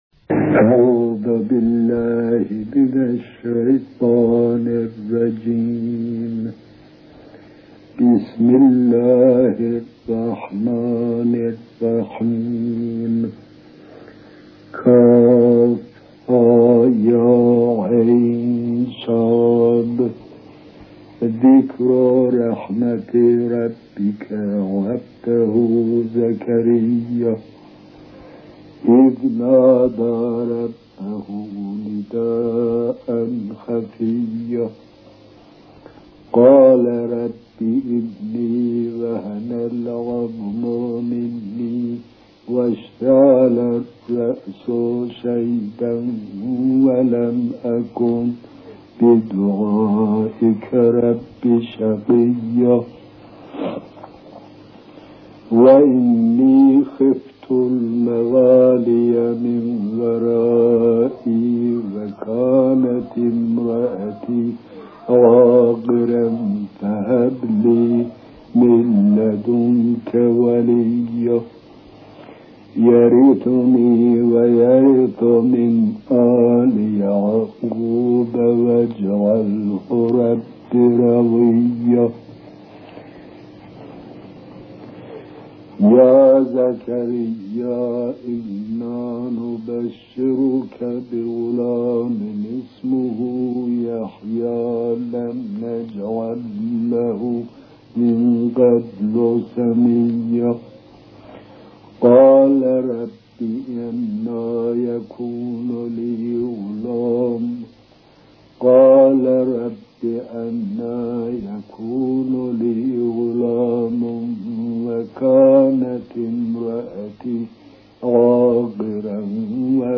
تلاوت سوره مبارکه مریم توسط علامه طباطبایی